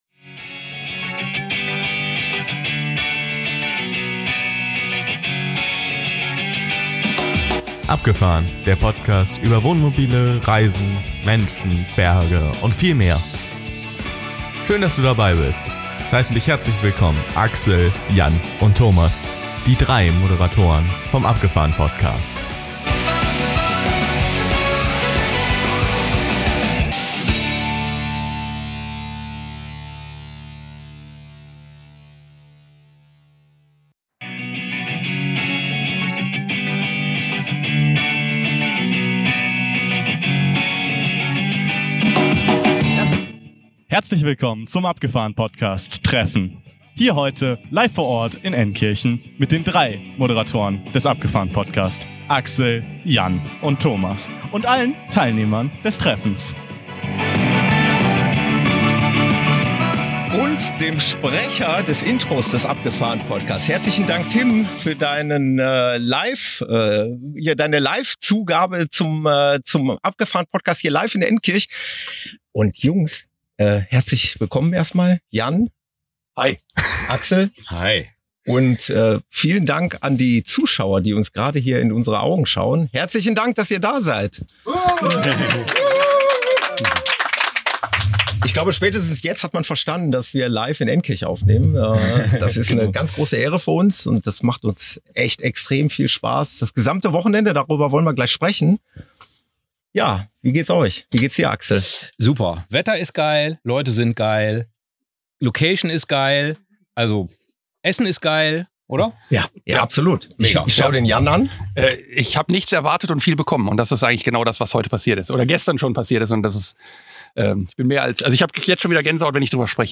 Die Höhepunkte des Treffens waren zweifellos die live vor Ort aufgenommene Podcast-Episode sowie die Kurzinterviews mit einigen von euch.